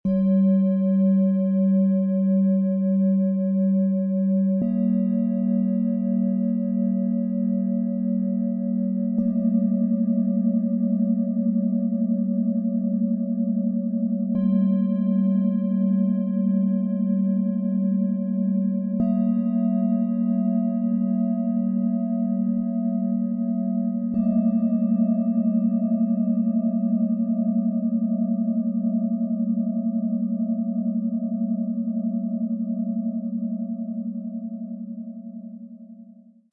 Sanfte Erdung, gefühlvolle Tiefe & harmonische Klarheit - Set aus 3 Planetenschalen für Gruppen und Kinder-Klangmassage, Ø 16,3 -17,9 cm, 2,12 kg
Die tiefen Schwingungen der ersten Schale helfen, sich zu erden und Veränderungen gelassen anzunehmen.
💖 Lass dich von diesen sanften, tragenden Klängen begleiten und erlebe ihre kraftvolle Ruhe.
Lausche dem Original-Ton dieser Schalen im Sound-Player - Jetzt reinhören und erlebe die sanften, tragenden Schwingungen, die den Raum mit harmonischer Energie füllen.
Erdung & Wandel - Tiefer Ton
Bihar Schale, Matt, 17,9 cm Durchmesser, 8,6 cm Höhe
Emotionale Balance & Intuition - Mittlerer Ton
Friedliche Harmonie & geistige Ruhe - Höchster Ton